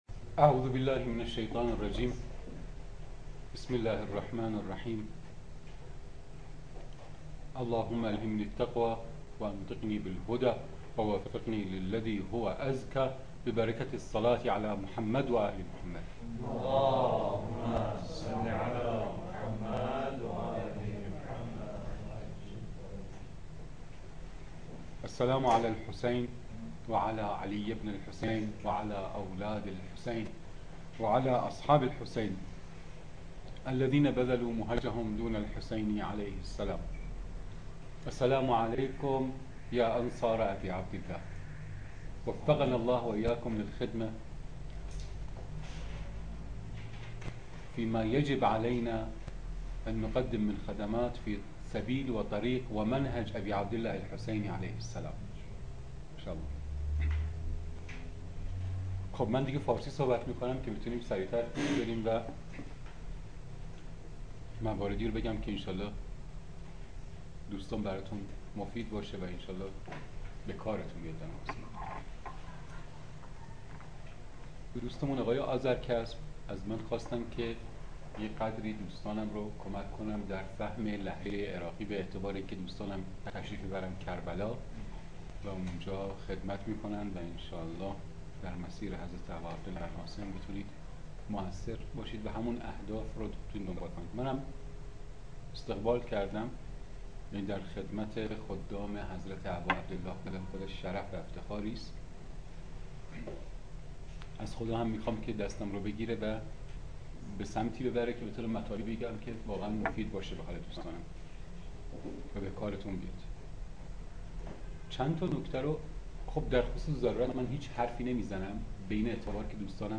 lahje araqi-1